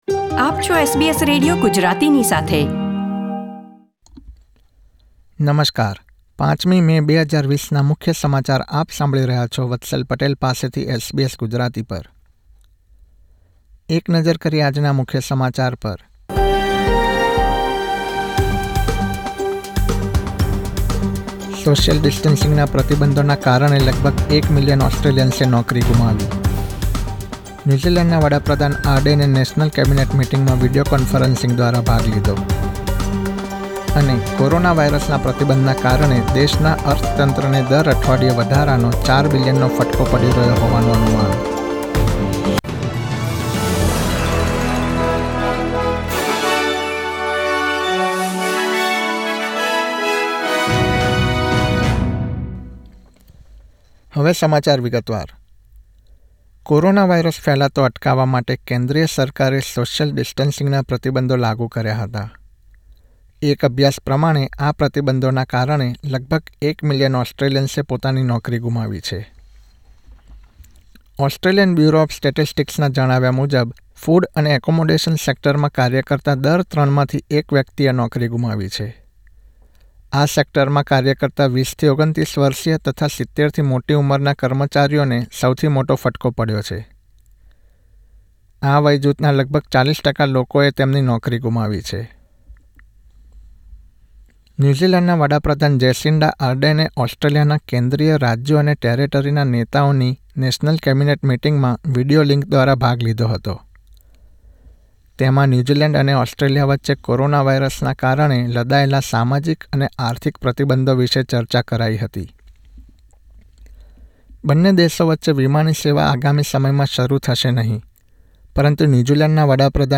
SBS Gujarati News Bulletin 5 May 2020